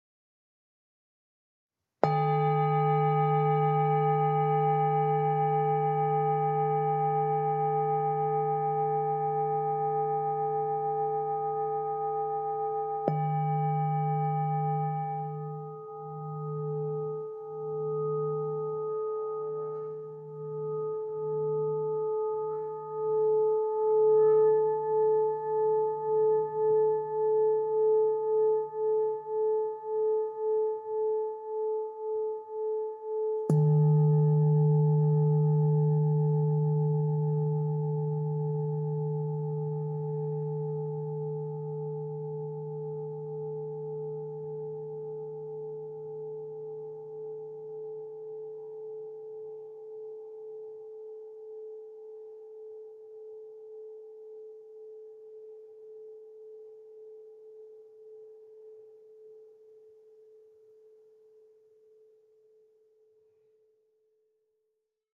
When softly tapped or rubbed, Sonic Energy singing bowls release a fascinating, multi-layered, and colorful sound that resonates deeply within the soul. Over a rich fundamental tone, entire waterfalls of singing overtones emerge to float freely in space and unfurl inside the body. Once the sound starts vibrating, it won't stop; even a minute later, a soft reverberation can still be felt.
With a bellied design created from additional hammering, these bowls generate a sustained deep tone that will fill a room with ambient sounds.